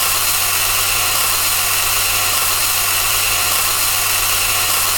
针对未来感电子声的PPT演示模板_风云办公